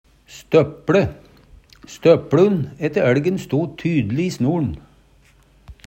støpLu - Numedalsmål (en-US)